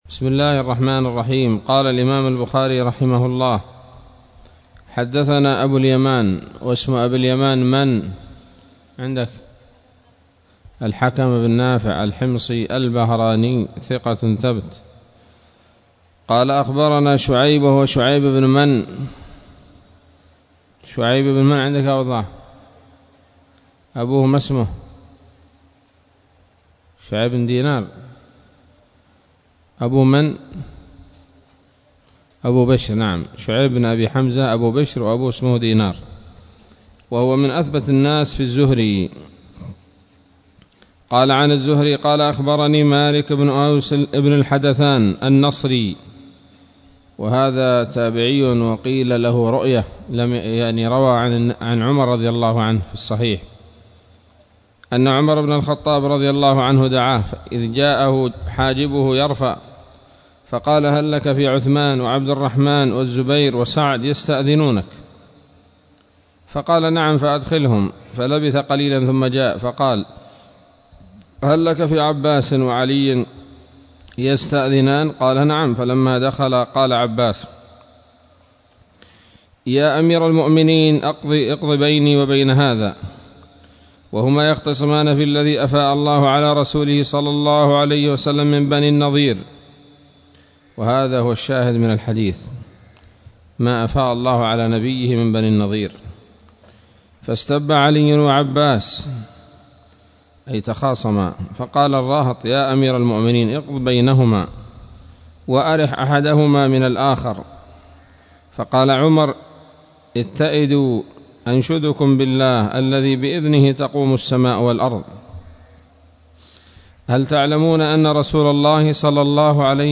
الدرس الخامس والعشرون من كتاب المغازي من صحيح الإمام البخاري